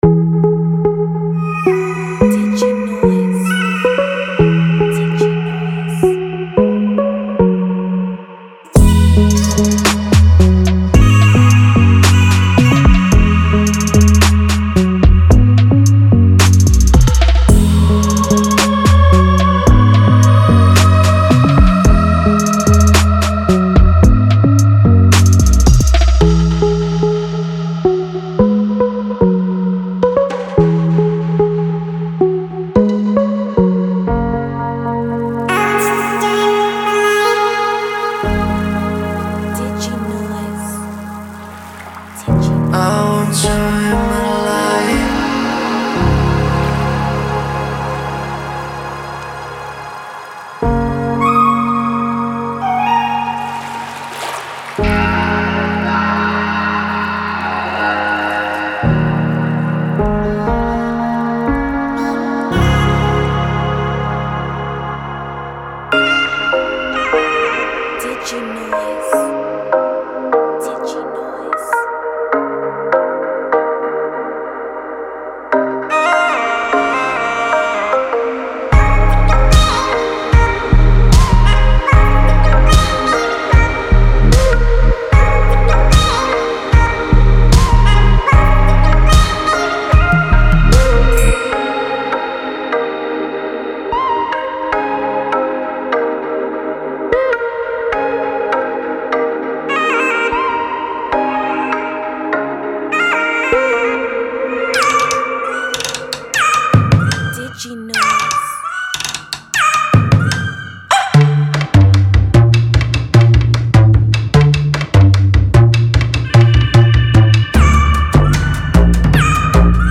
“人声”包含75个带有人声序列，单发和声音的文件。
本产品仅包含人声文件。